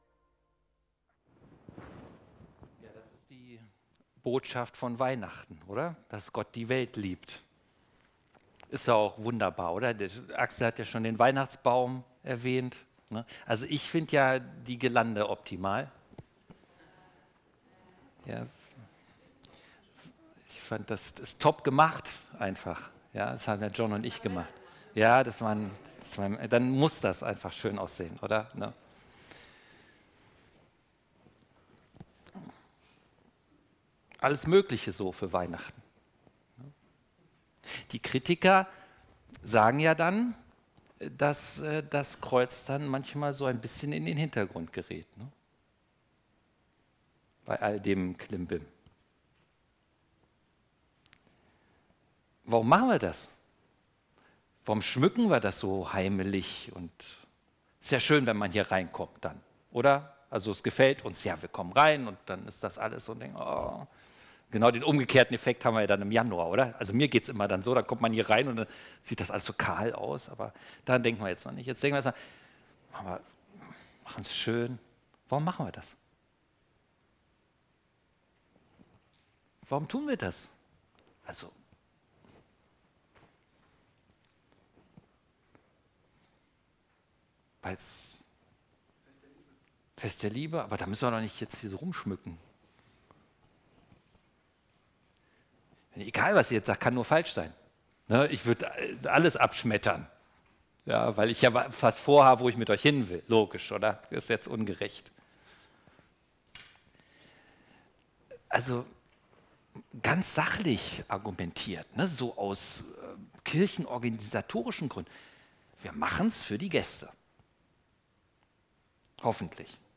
2024 in Gottesdienst Keine Kommentare 212 WATCH LISTEN